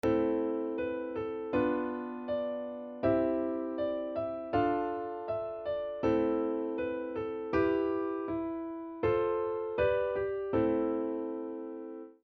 Мы будем использовать тональность ля минор и аккорды, которые соответствуют этому ладу.
• В четвертом такте завершение мелодии на A с аккордом Am подчеркивает стабильность и завершенность.
primer-dlya-lya-minora.mp3